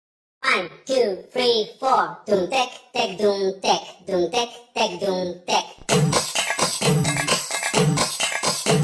با صدای بچه رباتیک